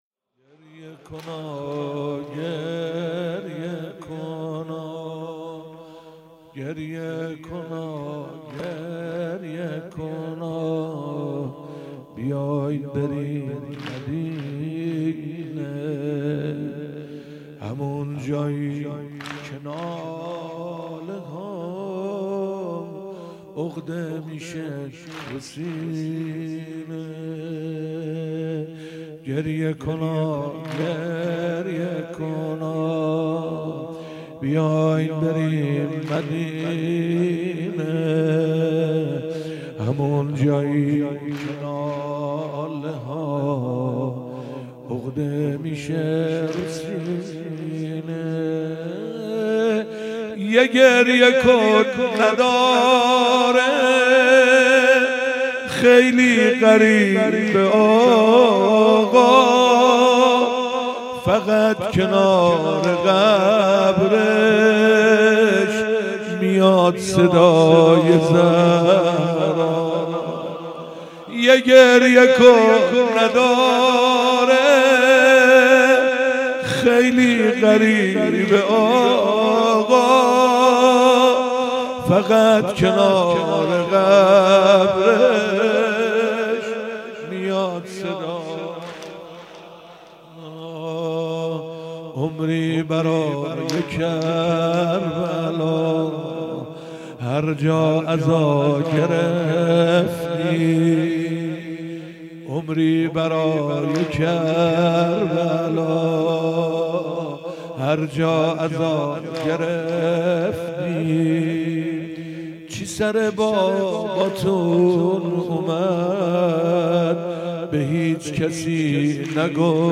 بیست و پنجم محرم 96 - هیئت فدائیان ولایت - واحد - گریه کنا
محرم 96